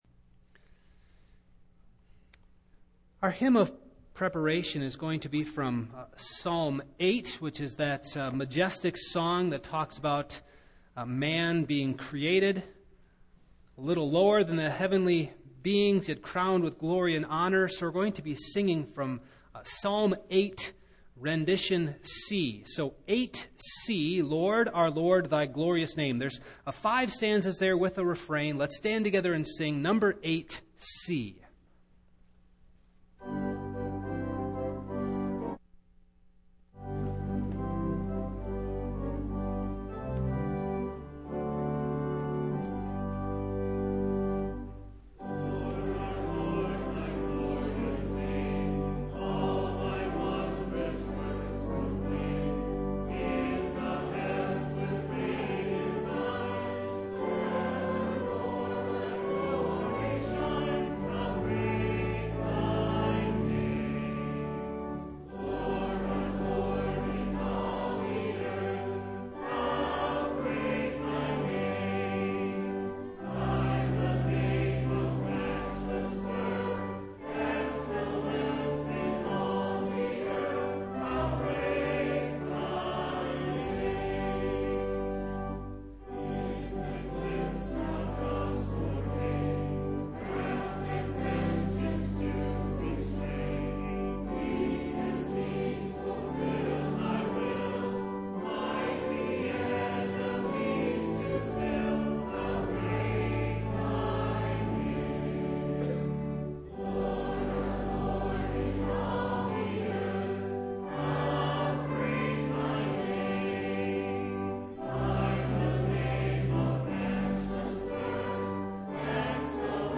Single Sermons
Service Type: Evening